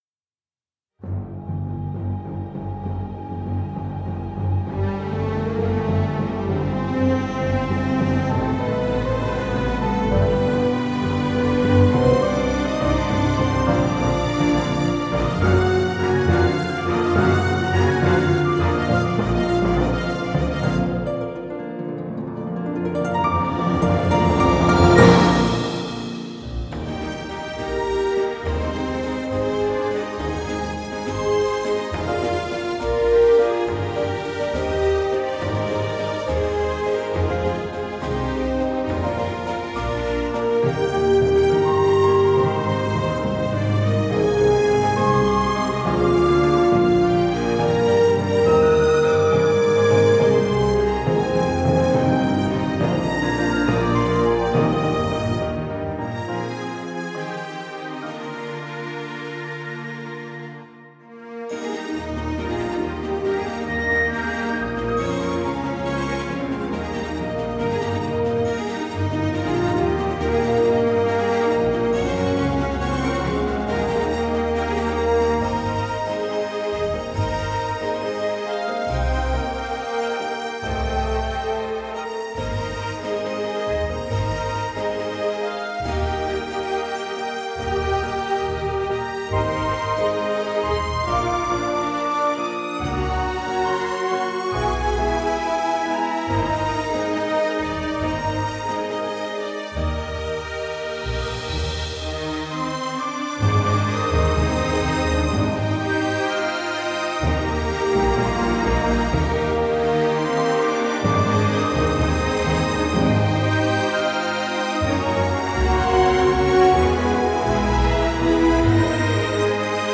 This is my own arrangement for pit orchestra, just over 4 minutes long.
cello solo